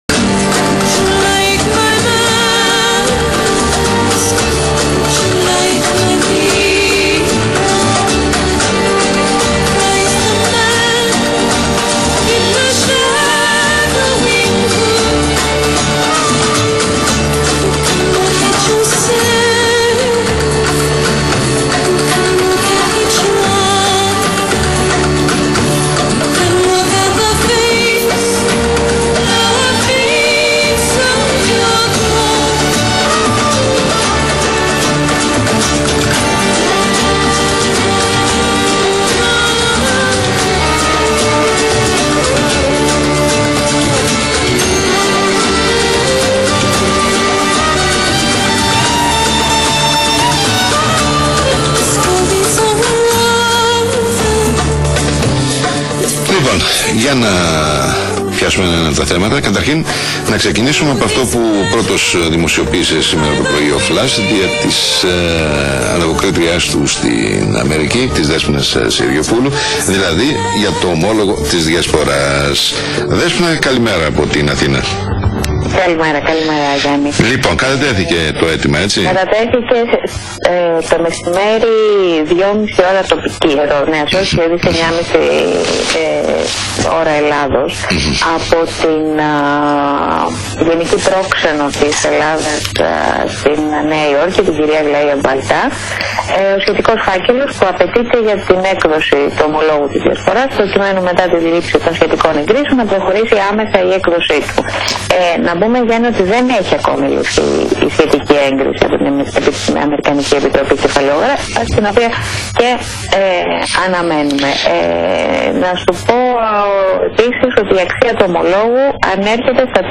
Ακούστε Live την είδηση όπως πρώτος την μετέδωσε ο Flash( (τις πρώτες πρωινές ώρες Ελλάδος) δια της ανταποκρίτριας